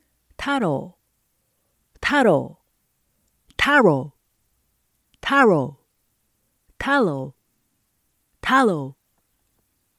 」 「 R 」 「 Lの発音比較
舌の位置と巻き具合がそれぞれ大きく異なる。
「 たう  –  たう  –  Taro  –  Taro  –  Talo  –  Talo 」 全長 10秒
英語音は 「 2音節 」 で、 第1音節に強いアクセント（ 強勢 ）。
【発音】  tɑ́rou